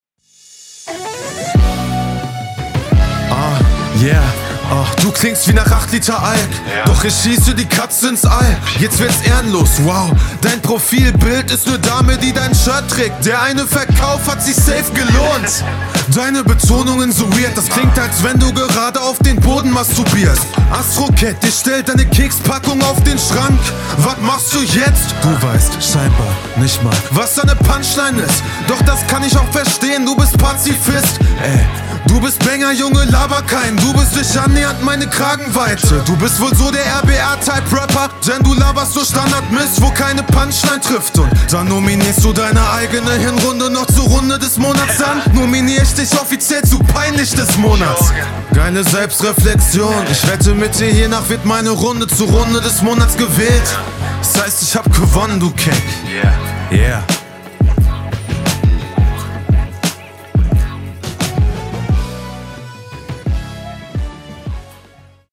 Sound richtig nice, find das rein stilistisch super geil.